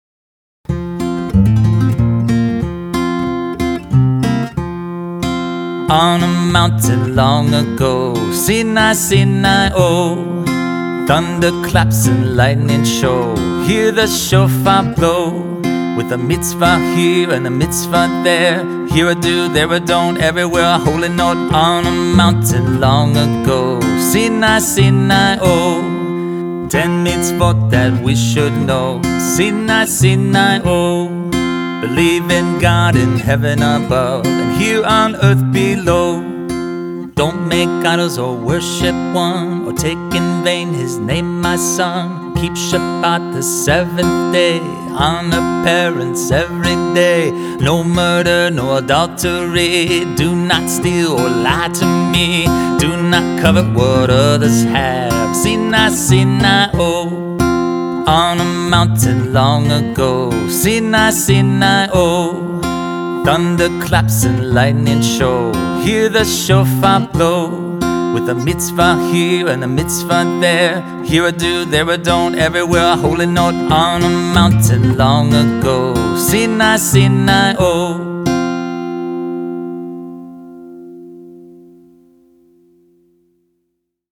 (To the tune of "Old McDonald Had a Farm")